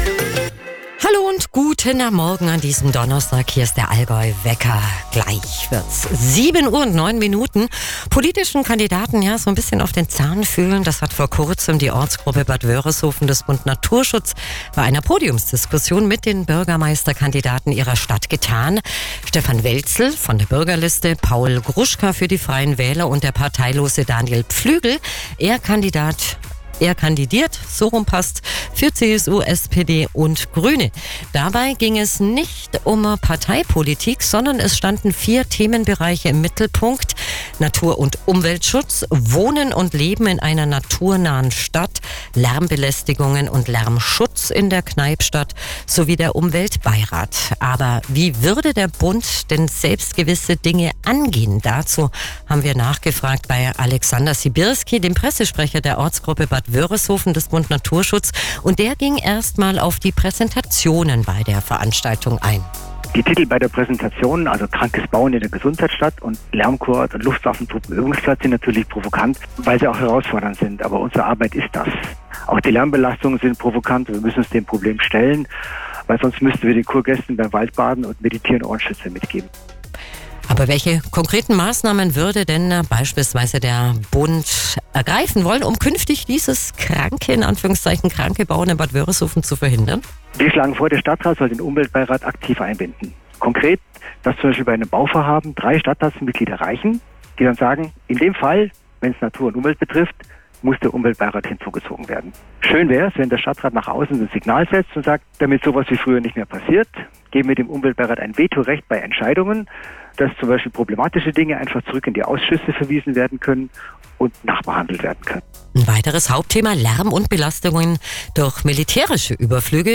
Radiointerview vom 26. Februar bei Radio AllgäuHIT   ⇐⇐⇐